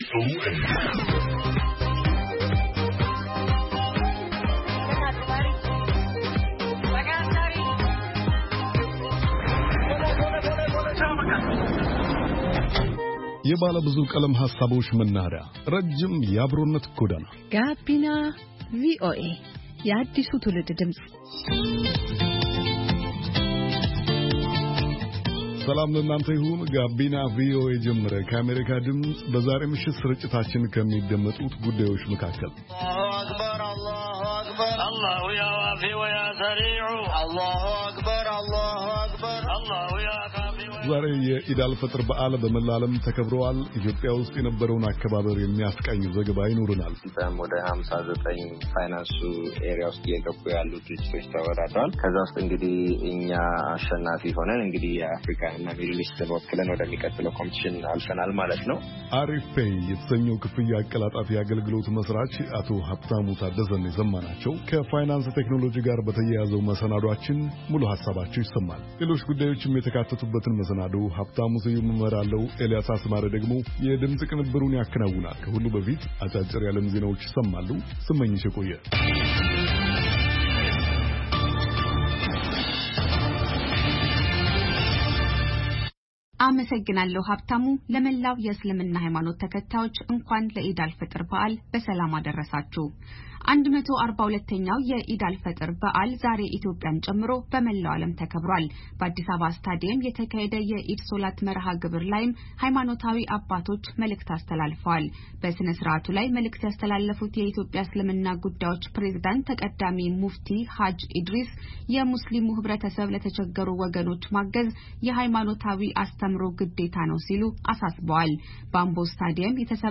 Gabina VOA is designed to be an infotainment youth radio show broadcasting to Ethiopia and Eritrea in the Amharic language. The show brings varied perspectives on issues concerning young people in the Horn of Africa region.